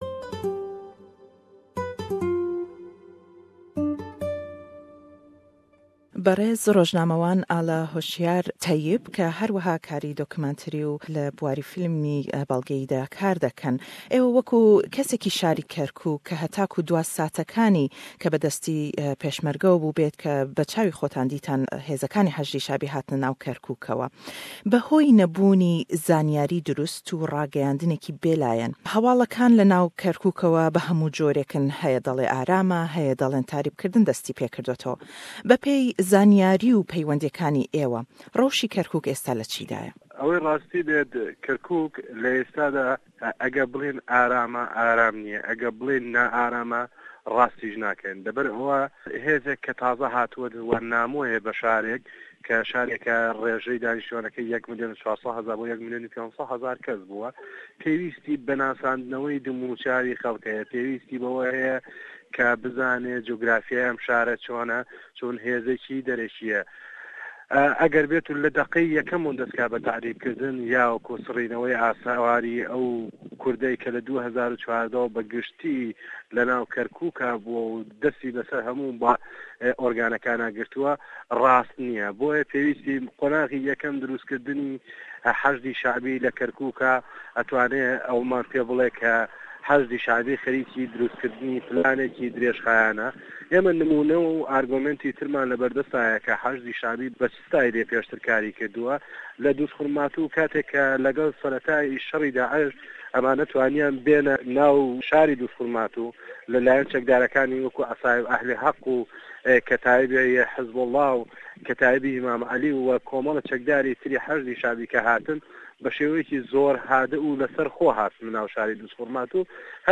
Ême pirsiyarî lê dekey sebaret be rewşî Kerkûk û aye rêkewtinekan bo radestkirdinewey Kerkûk (ke wekû degûtrêt) be pê plan berêwe çûn' Emane û çendîn pirsiyarî gringî dîke le em lêdwane da....